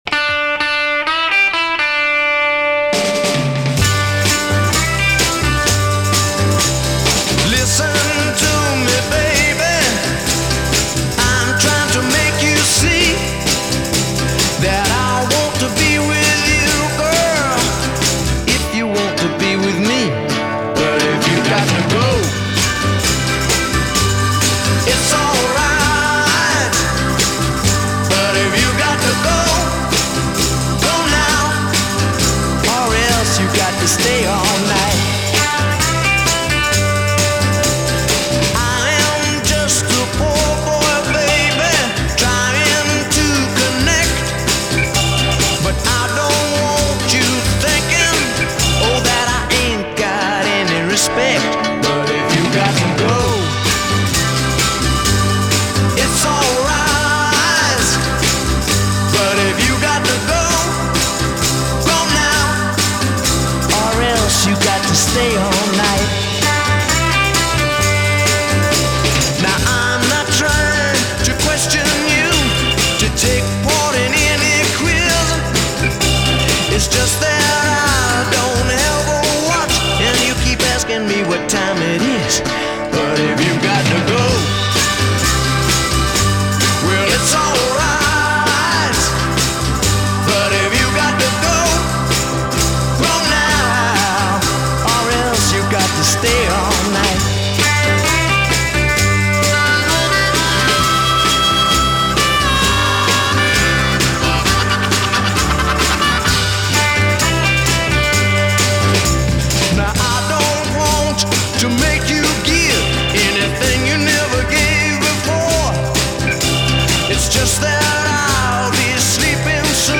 • Категория:Музыка для твиста